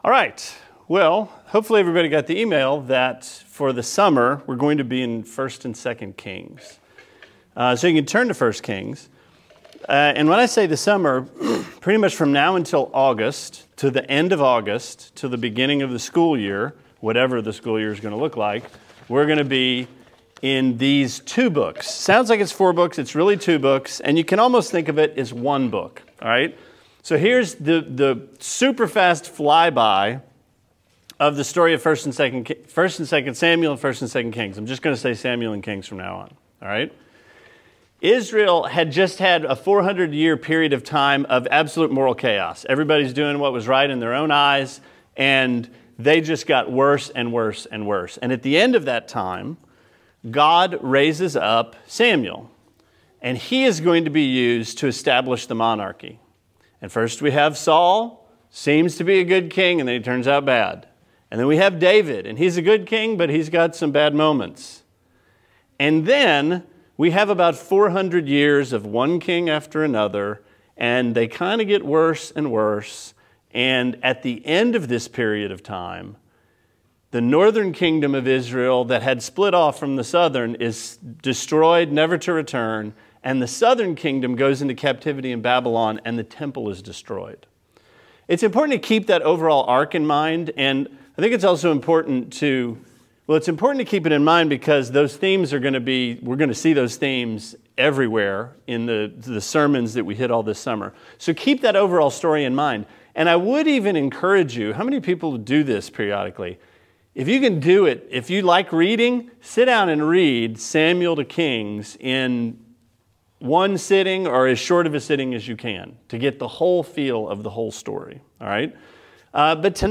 Sermon 6/5: 1 & 2 Samuel Introduction